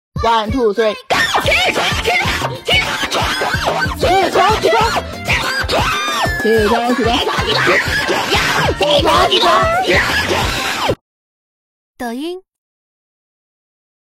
魔性笑声的闹钟、魔性搞怪闹钟铃声、起床铃声搞笑